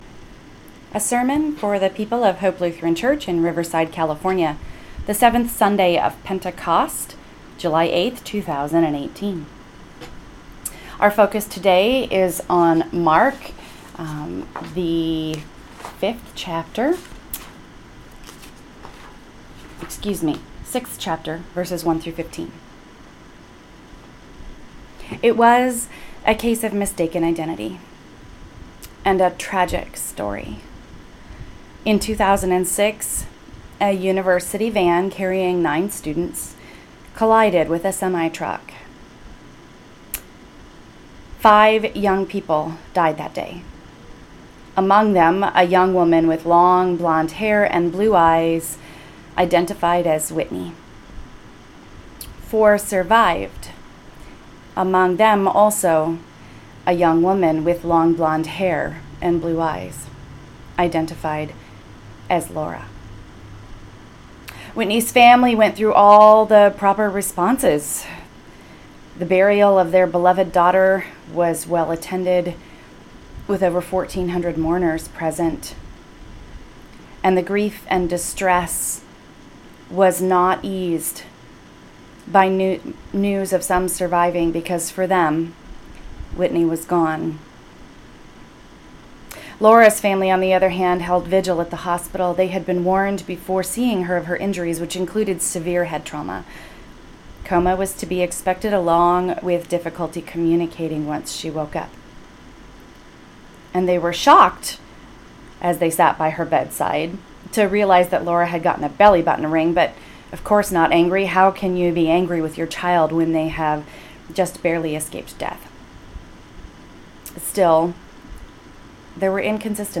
Listen (below) or read on for my sermon on Mark 6:1-15